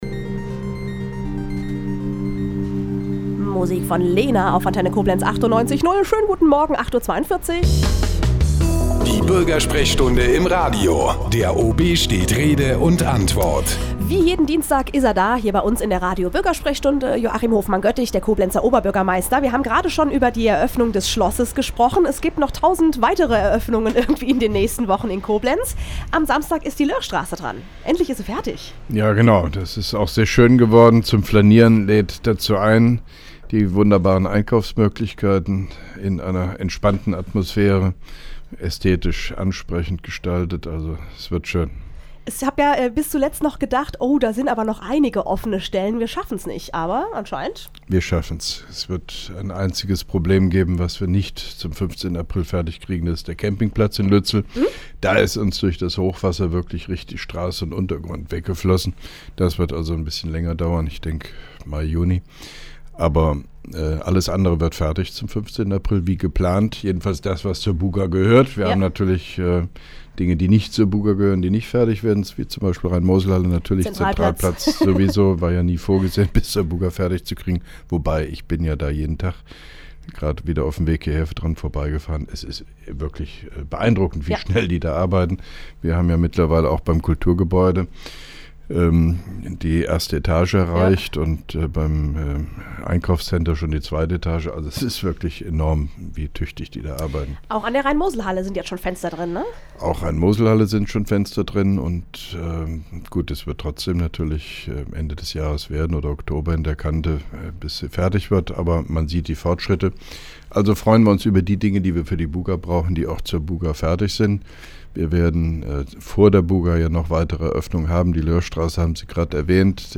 (3) Koblenzer Radio-Bürgersprechstunde mit OB Hofmann-Göttig 05.04.2011